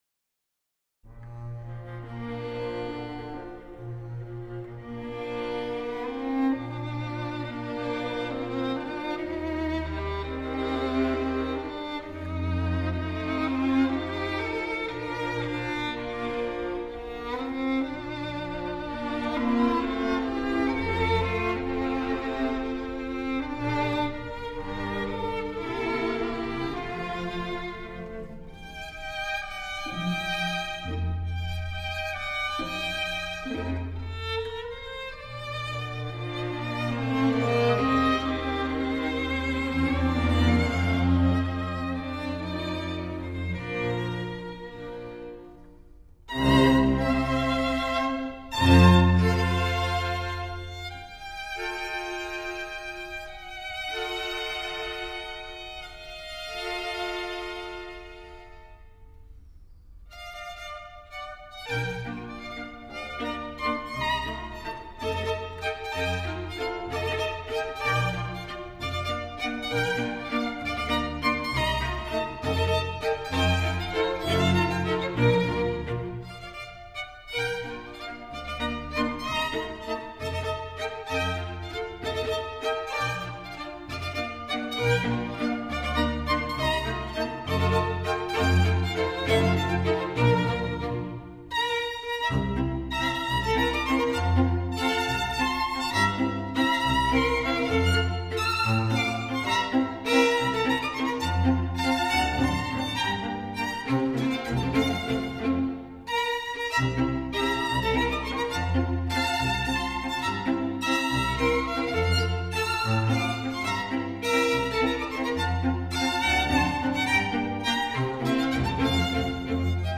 古典：HI-FI室内乐合奏（DDD）
音乐类型: HI-FI室内乐
同时该唱片也是运用了3D技术的录音，有着清晰的解析力。